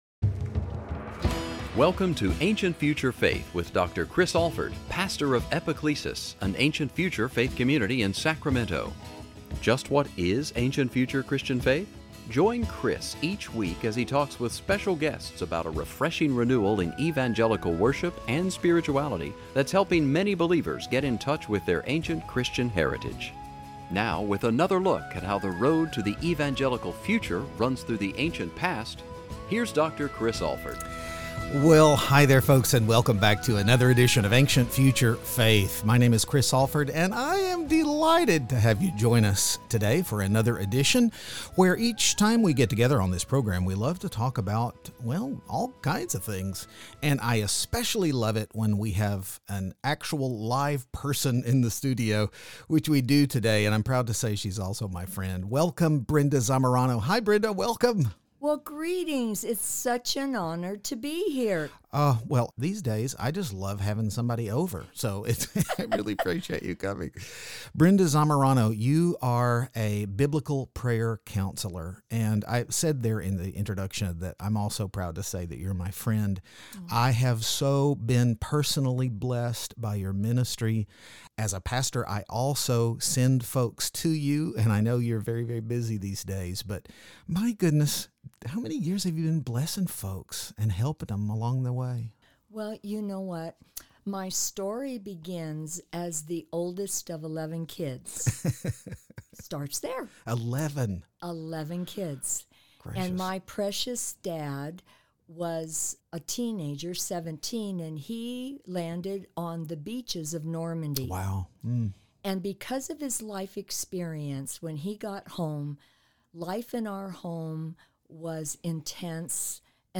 Series: Ancient-Future Faith on KFIA